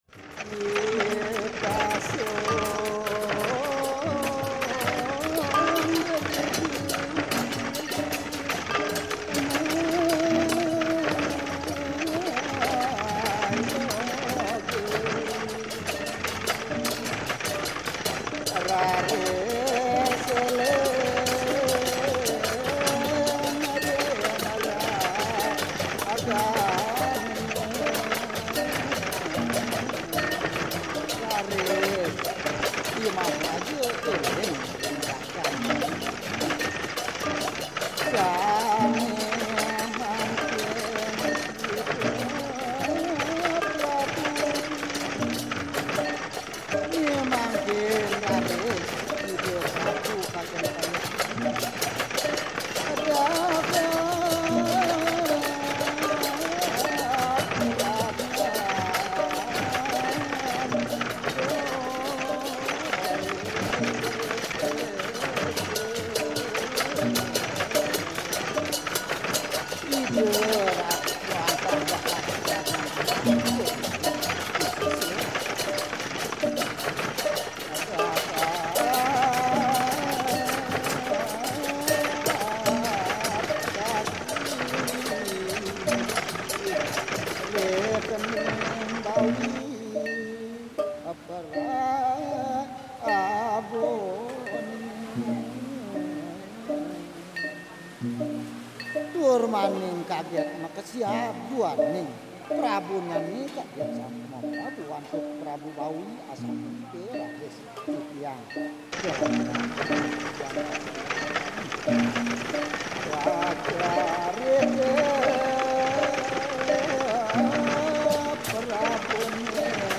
Bali waves reimagined